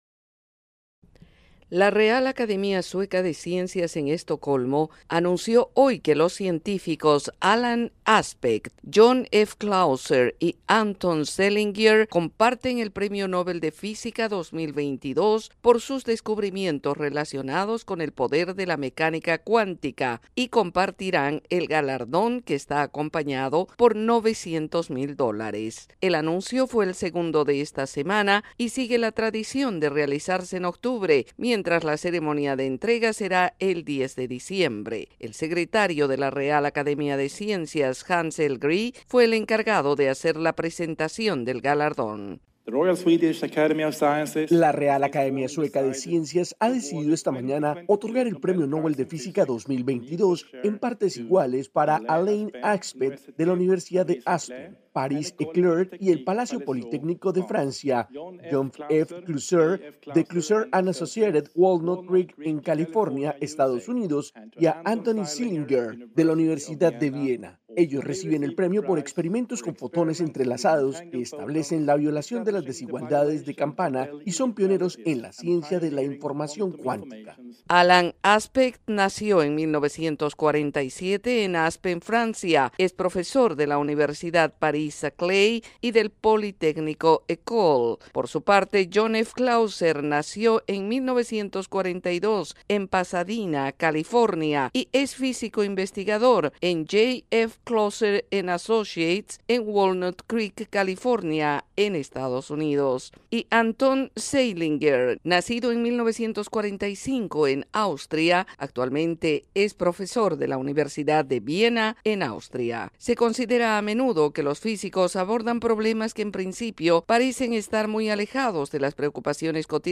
Voz de América en Washington DC.